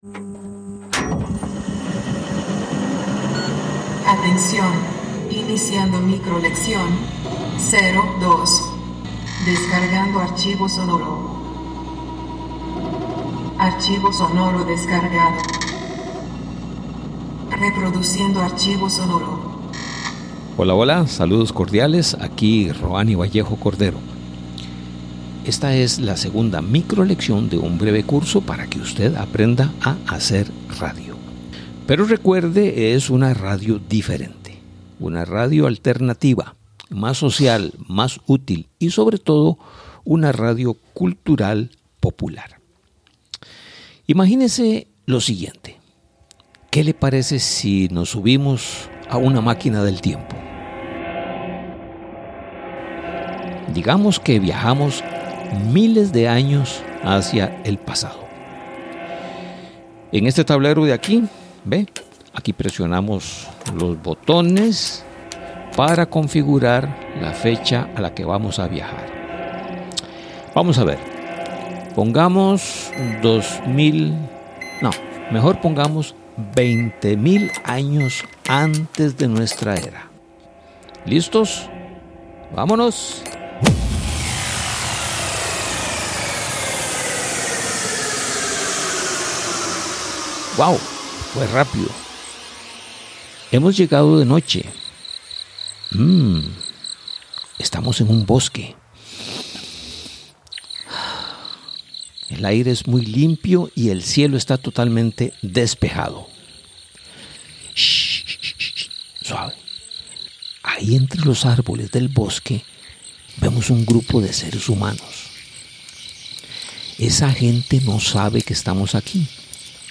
Micro-lección 02: Las personas que hacemos radio alternativa somos contadores de historias.